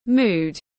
Mood /muːd/